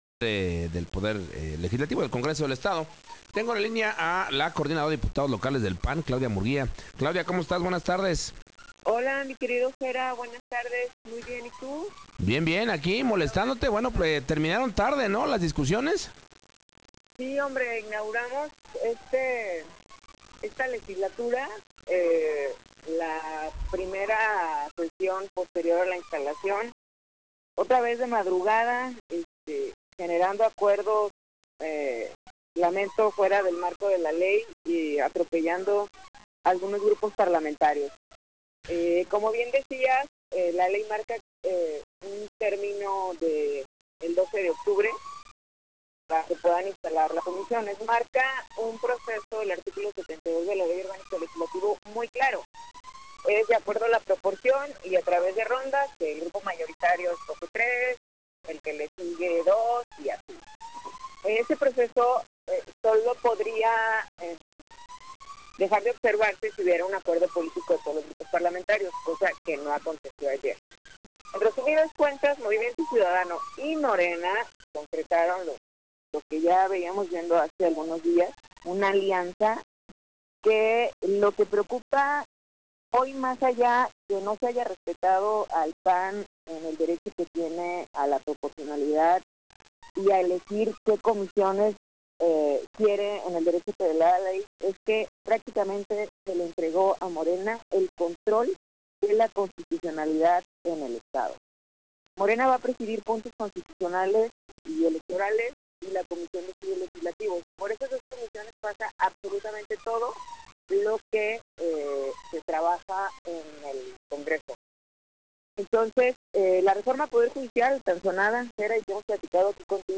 La Entrevista con Claudia Murguía
Claudia Murguía, coordinadora de la bancada del PAN señaló en Entrevista para la Segunda Emisión del Noticiero En Punto, su inconformidad y la de su grupo parlamentario por lo que ella considera una alianza entre Morena y Movimiento Ciudadano, que es preocupante ya que se le entregó a Morena el control de la constitucionalidad del Estado al presidir, Puntos Constitucionales y Electorales así como la Comisión de Estudios Legislativos, comisiones por las que pasa todo lo que se trabaja en el Congreso, para dar un ejemplo “La Reforma del Poder Judicial estará en manos de Morena”.